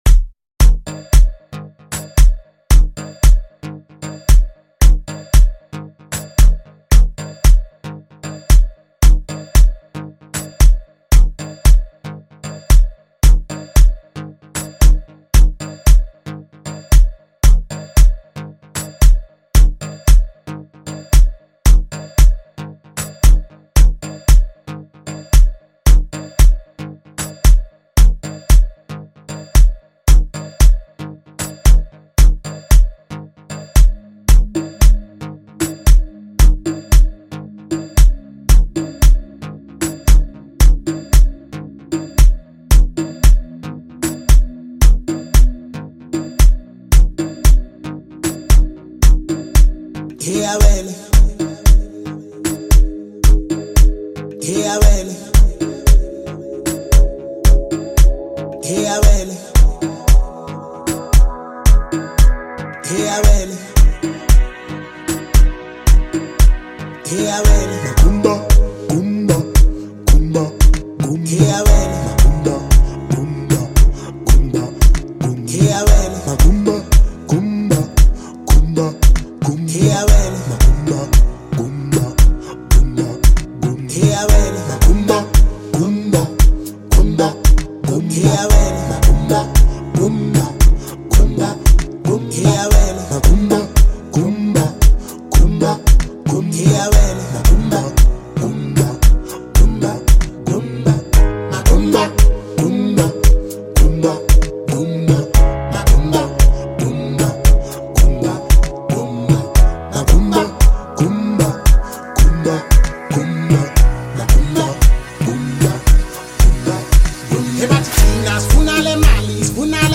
Category: Amapiano